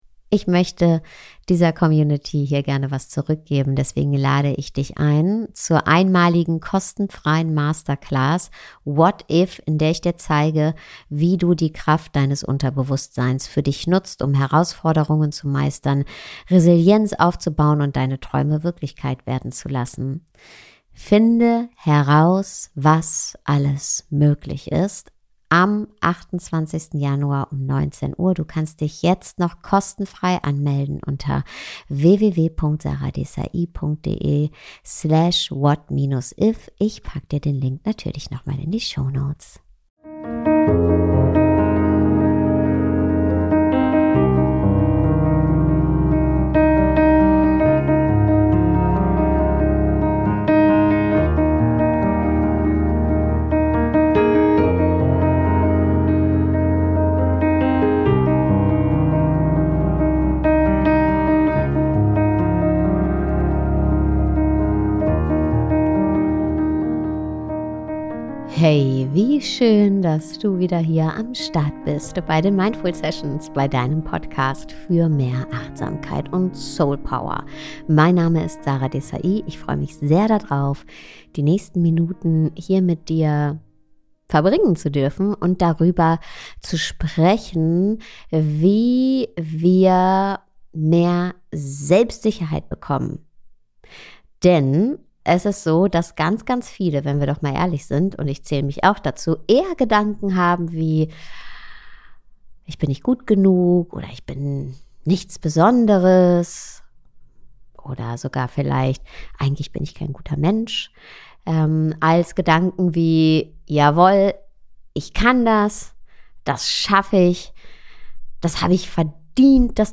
Gesunder Selbstwert - Interview mit Aljosha Muttardi